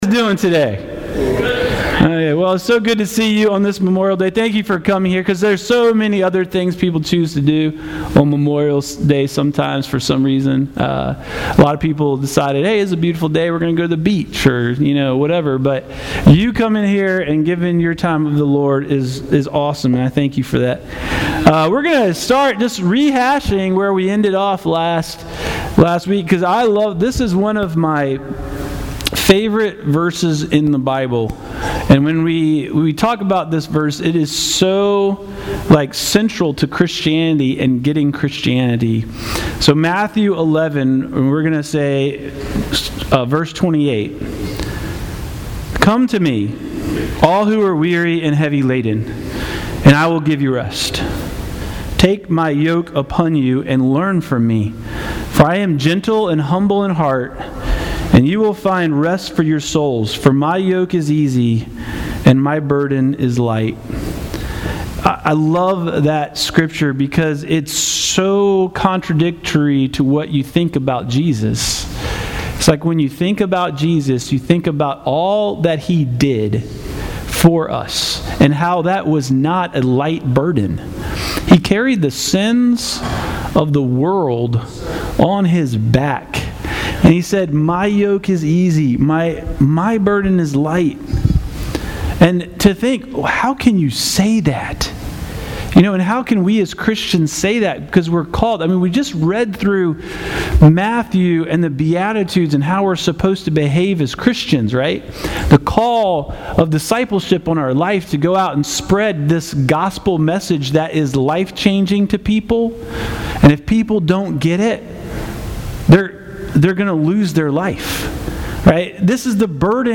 » Sermons